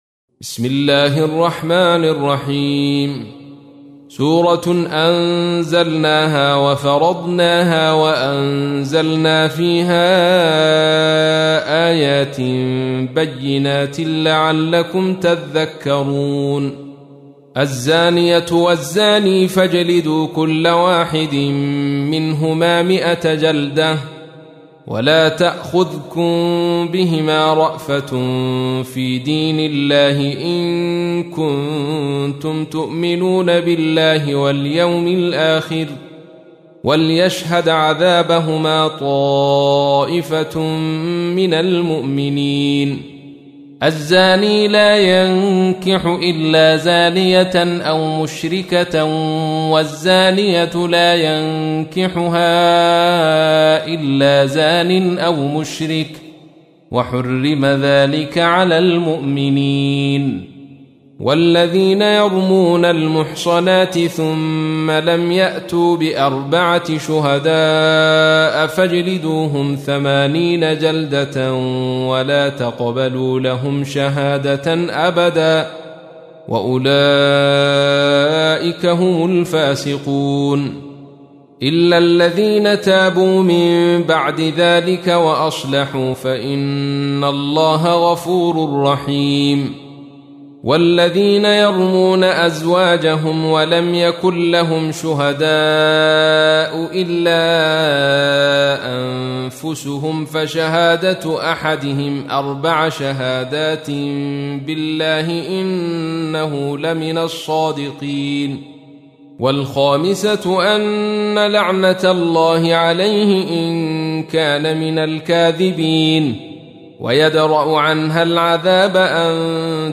تحميل : 24. سورة النور / القارئ عبد الرشيد صوفي / القرآن الكريم / موقع يا حسين